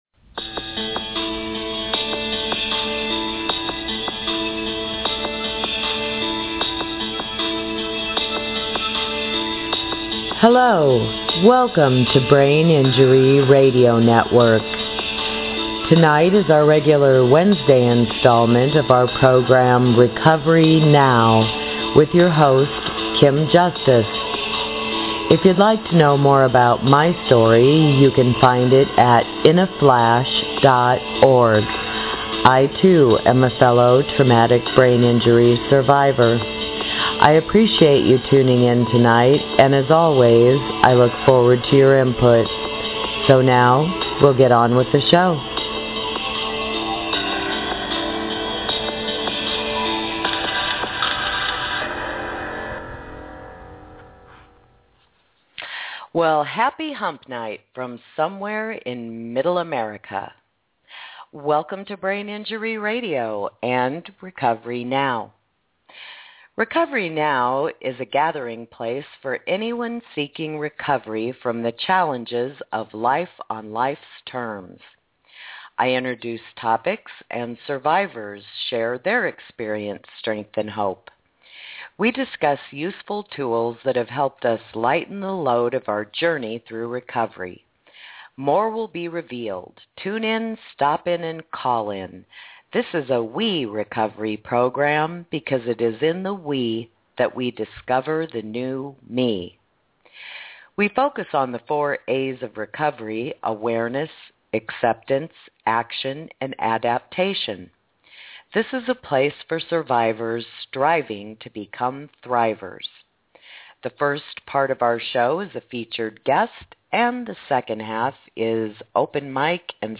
Open Mic Night to share your positive memories of Brain Injury Radio as we kick off the week of our 4th Anniversary!
This week, we explore the other side of the coin. I also have several new Indie Artists to play, as well as your favorite requests.